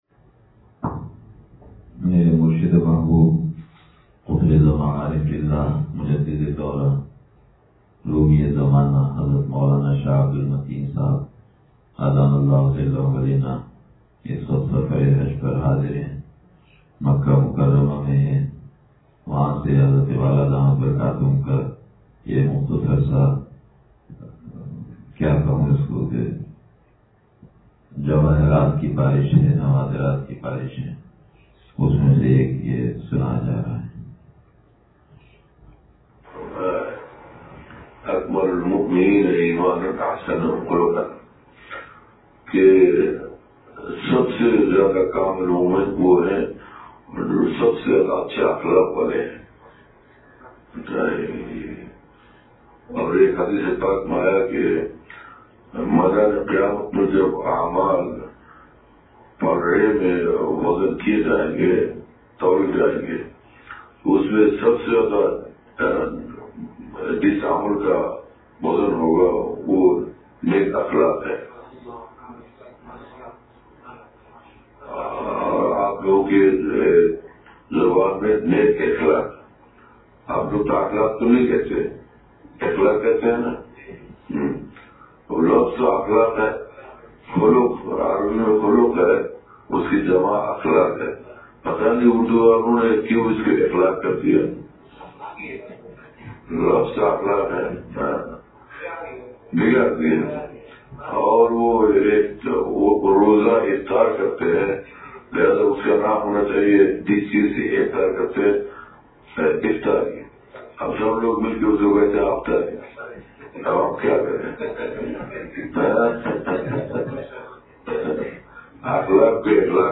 بیان – اتوار